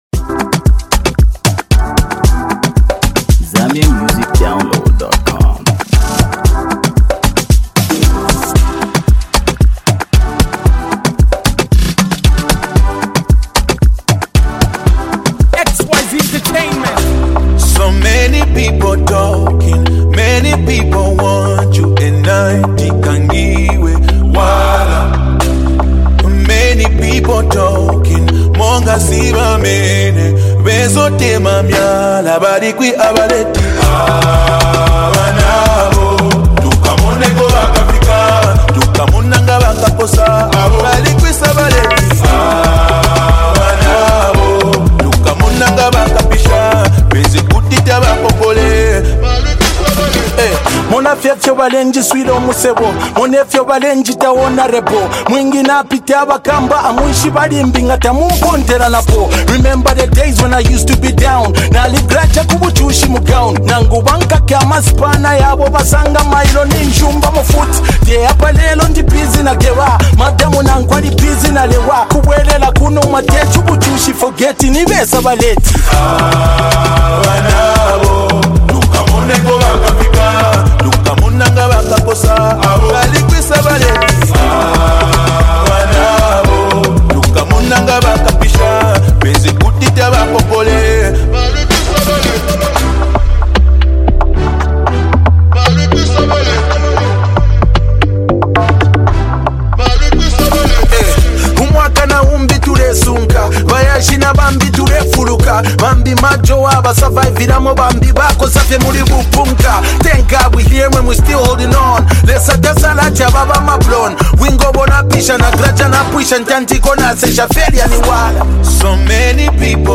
With its compelling message and infectious rhythm